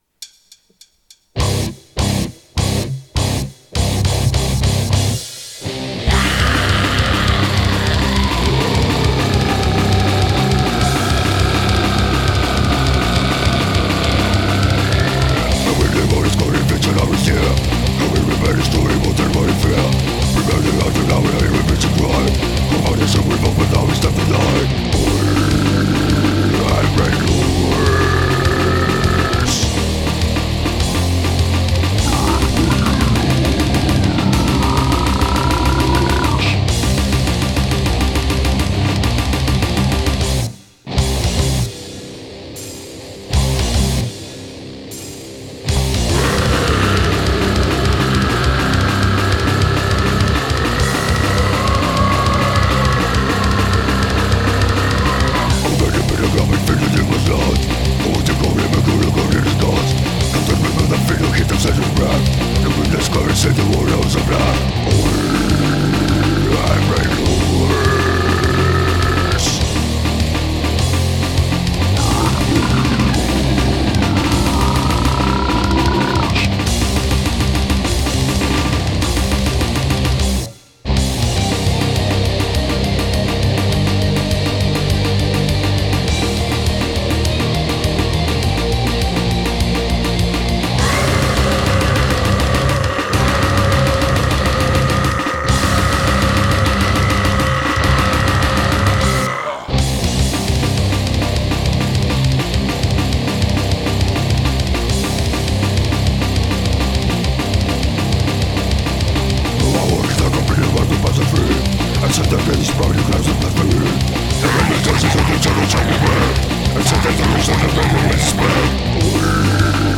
basses, guitars
vocals
drums
lead guitar